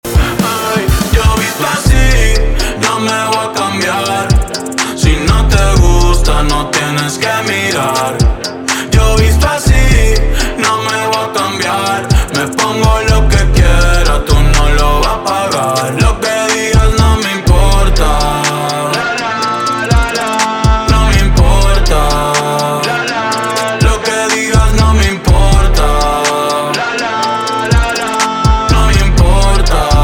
Urbano latino